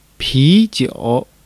pi2-jiu3.mp3